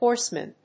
/ˈhɔrsmʌn(米国英語), ˈhɔ:rsmʌn(英国英語)/